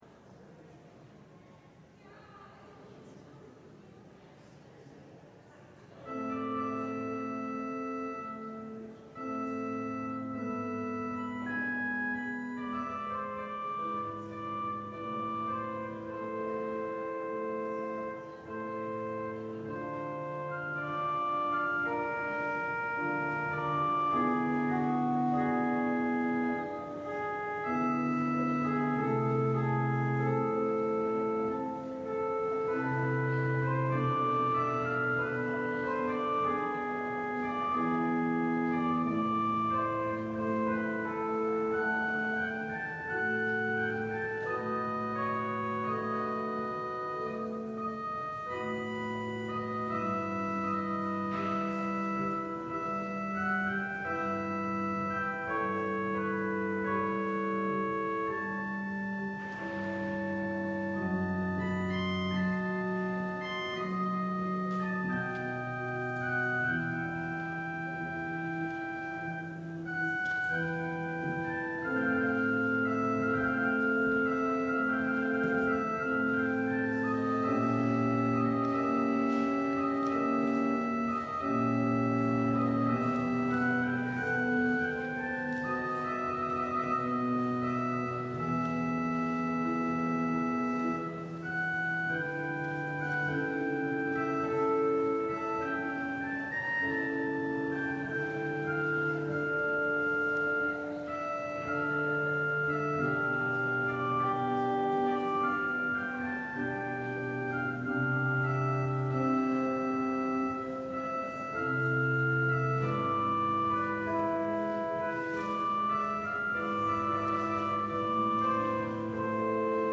LIVE Evening Worship Service - Jesus at the Graveside
Congregational singing—of both traditional hymns and newer ones—is typically supported by our pipe organ.